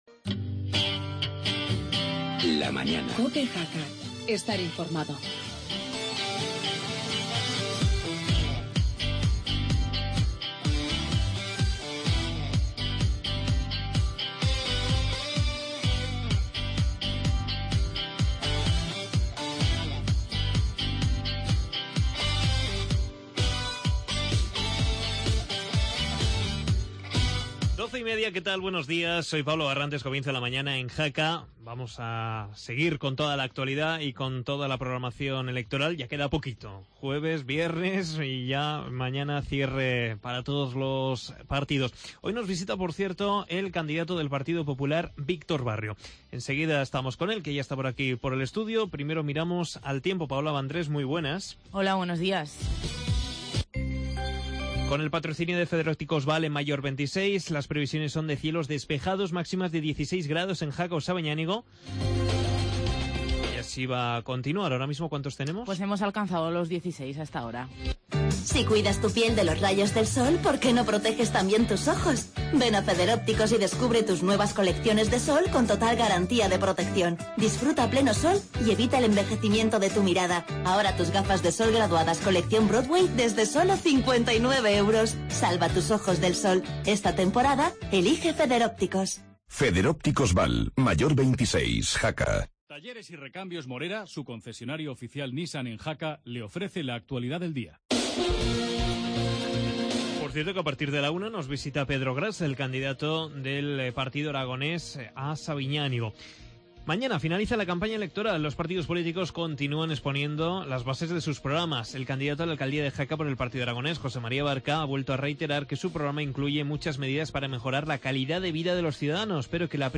Primera tertulia municipal tras las elecciones